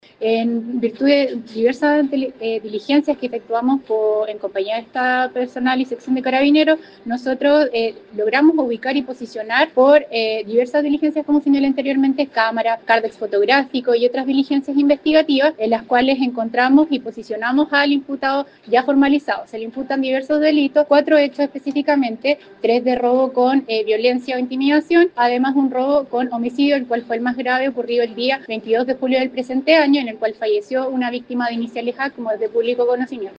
declaración